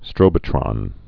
(strōbə-trŏn)